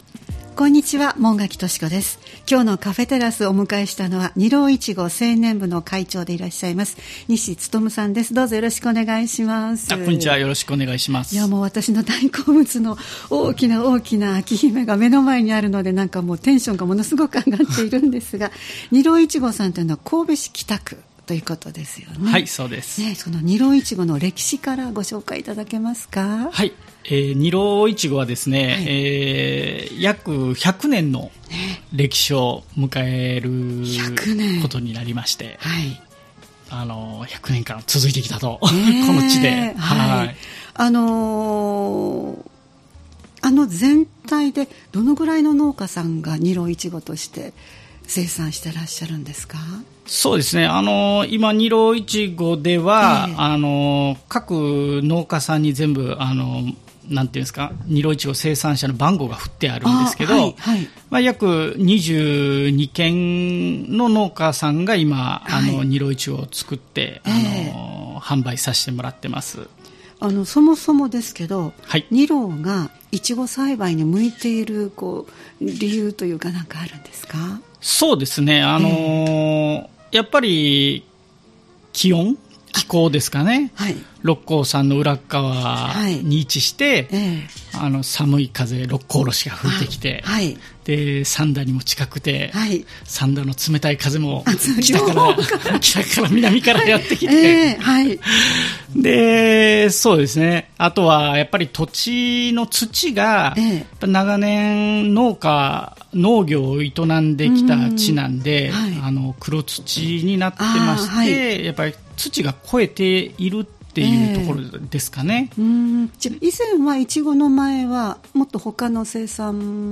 様々なゲストをお迎えするトーク番組「カフェテラス」（再生ボタン▶を押すと放送が始まります）